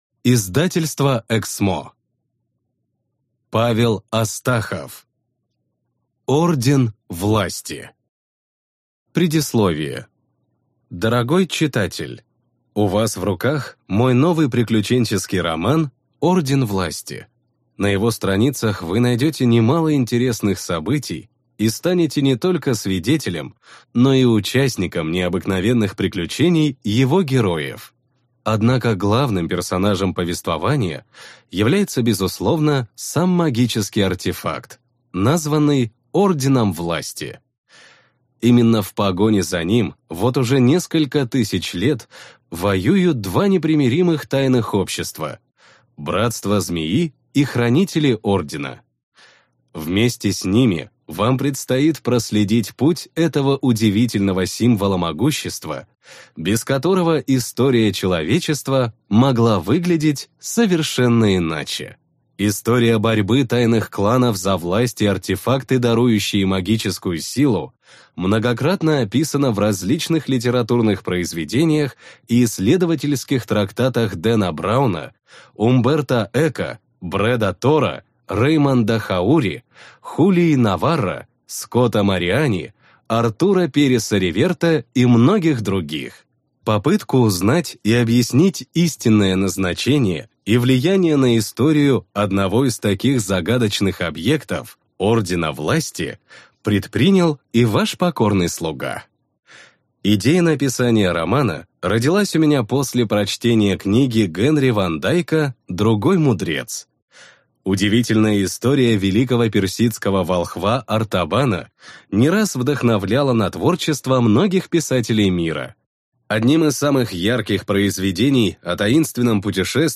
Аудиокнига Орден Власти | Библиотека аудиокниг